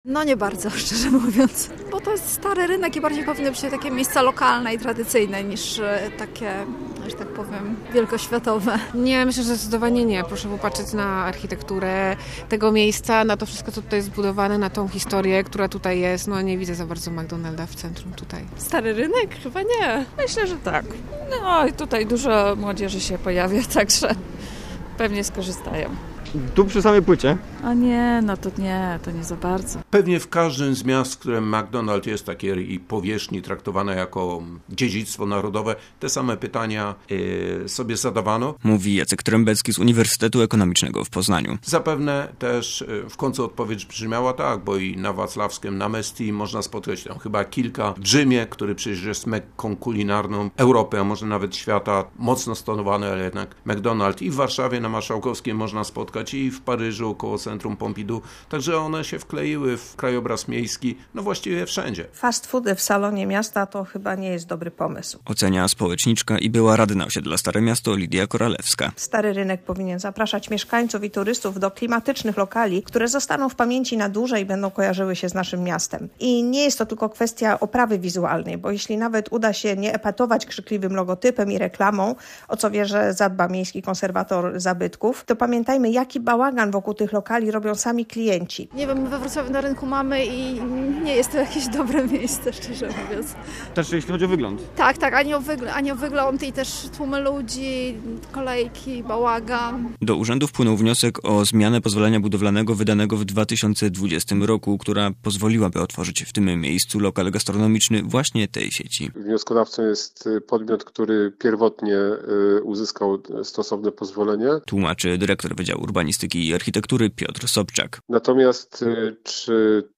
Takie plany nie podobają się mieszkańcom, z którymi rozmawiał nasz reporter.
- mówią mieszkańcy.